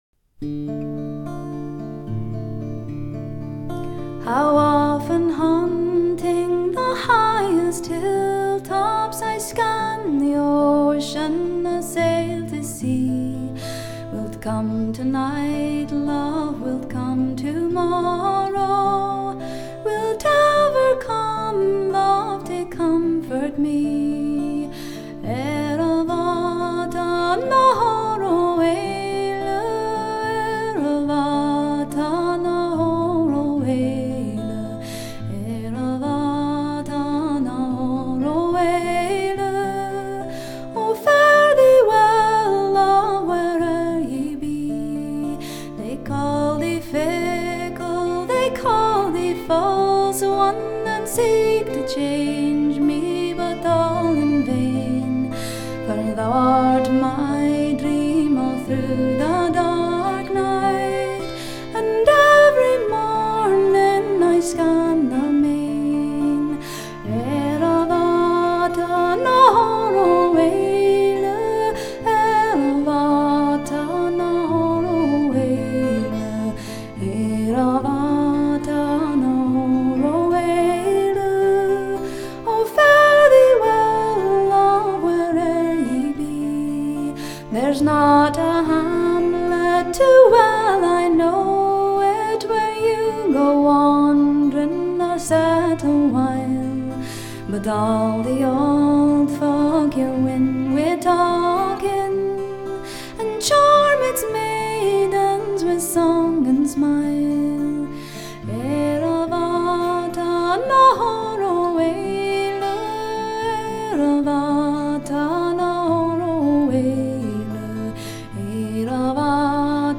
以SACD多声道环绕及双声道立体声，双重DSD模式制作呈现